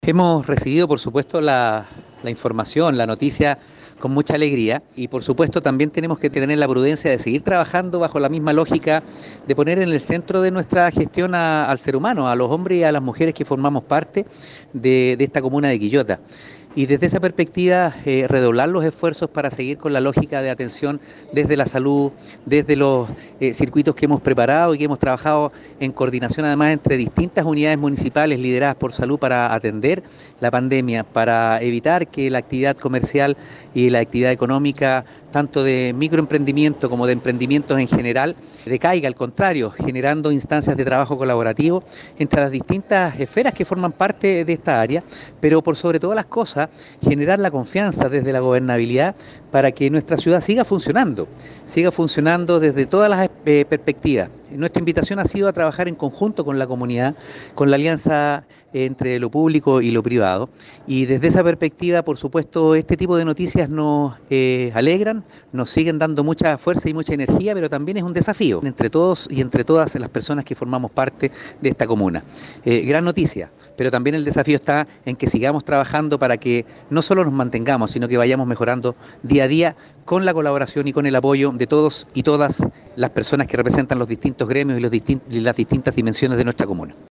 Alcalde-Oscar-Calderon-Sanchez-1.mp3